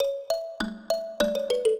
mbira
minuet10-10.wav